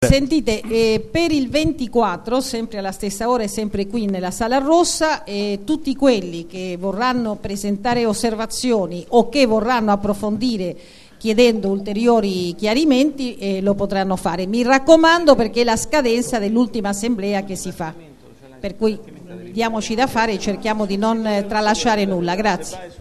Registrazione integrale dell'incontro svoltosi il 15 luglio 2014 presso la Sala Rossa del Municipio VII, in Piazza di Cinecittà, 11
31-fantino Ana Susana Fantino, Presidente del VII Municipio